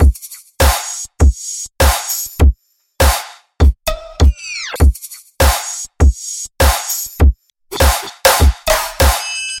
经典硬鼓
描述：一个EDM鼓或未来低音/rap鼓？
标签： 110 bpm Trap Loops Drum Loops 1.62 MB wav Key : Unknown
声道立体声